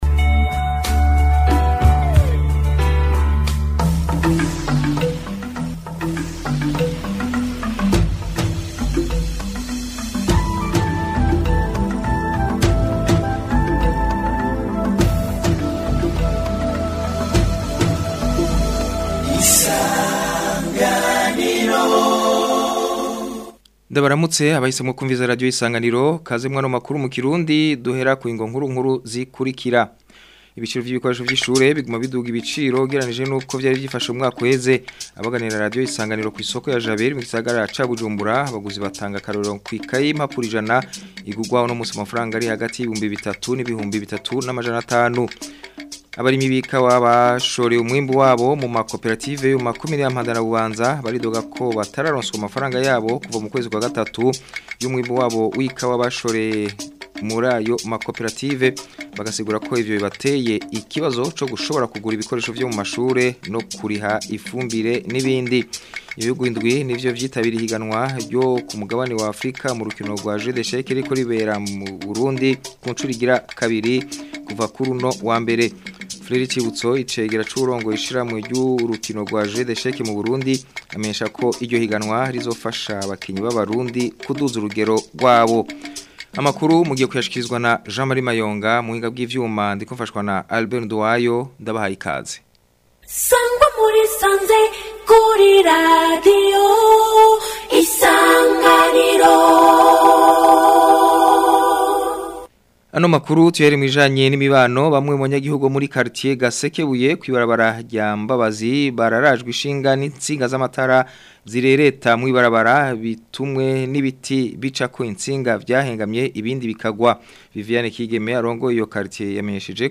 Amakuru yo ku wa 18 Myandagaro 2025